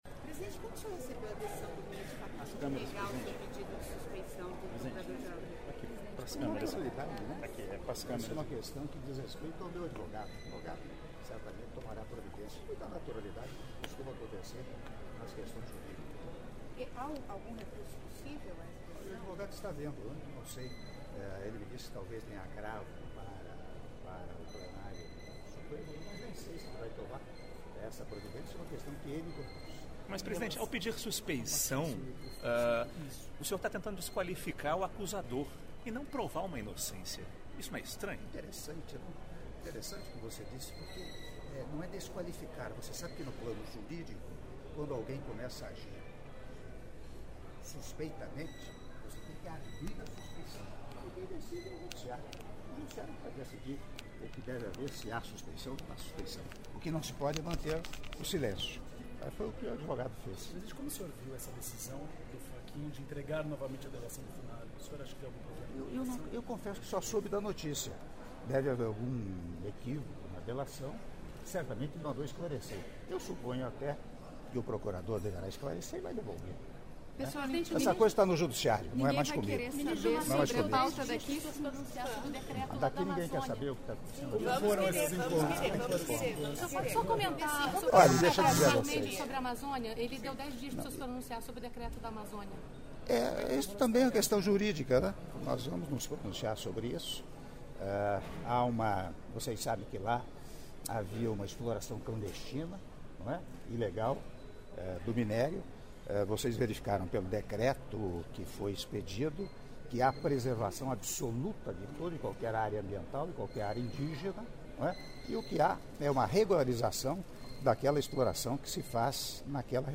Áudio da entrevista coletiva concedida pelo Presidente da República, Michel Temer, após encontro com empresários chineses - Pequim/China - (05min18s)